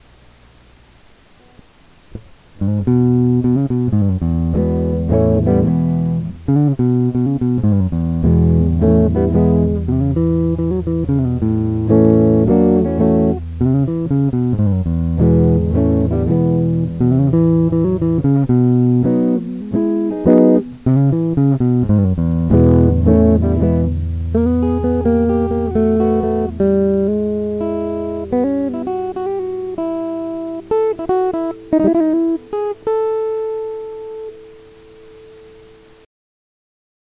Walked out with a new electric guitar .... test on micro less 020
A real mellow and rich tone to my ears.
The Tom Jones of guitars right there..superb tone.
Really a beautiful tone coming out of my Vox amp. And I do like it on the bassy side.